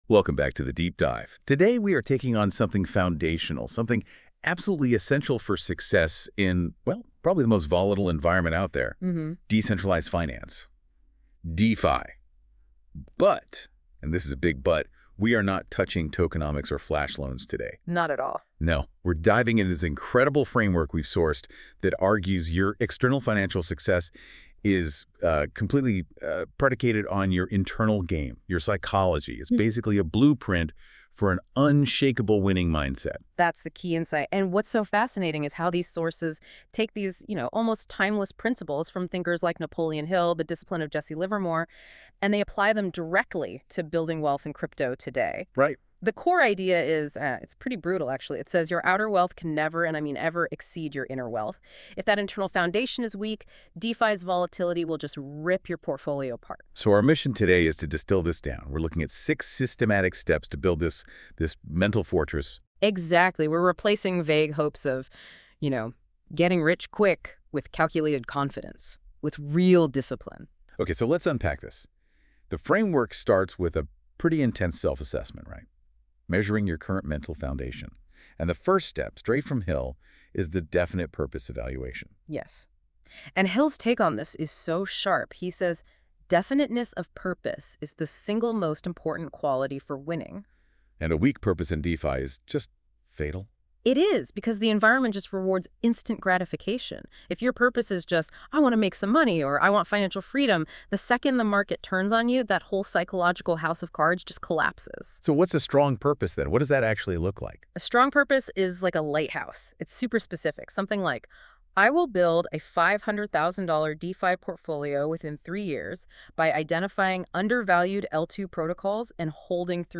hashtag🎧 Lesson Podcast